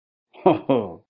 Смешной звук от Говорящего Бена